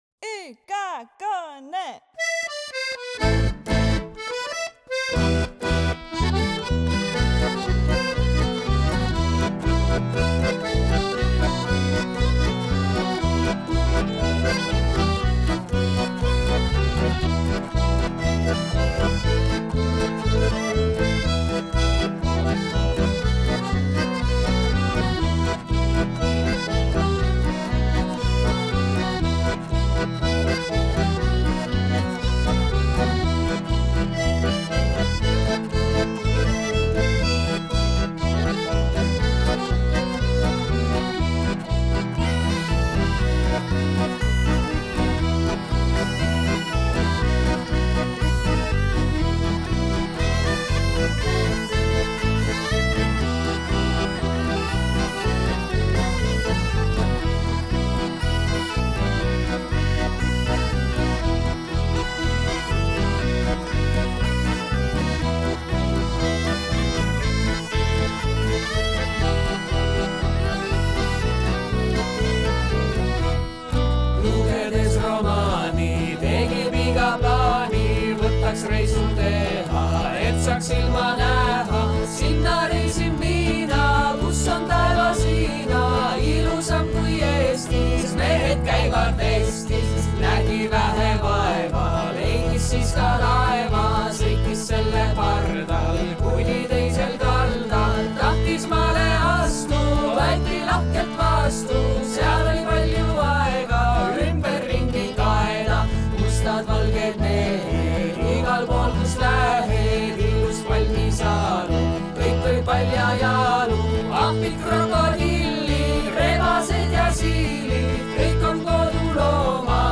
mille mängivad Viljandi kultuuriakadeemia tudengid